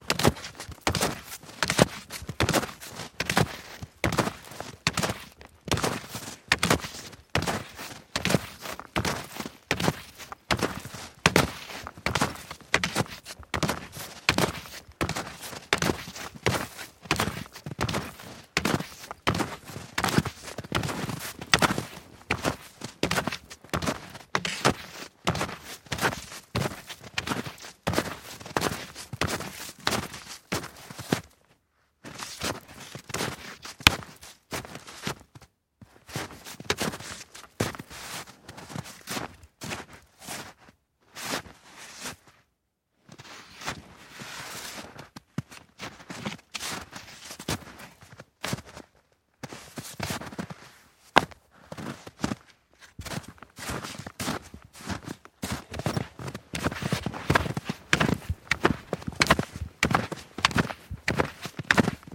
冬天" 脚步声 雪鞋 老木头1 挤满融化的雪 中速和停止1
描述：脚步雪鞋老wood1包装融雪雪中速和stops1.flac
Tag: 包装 脚步声 雪鞋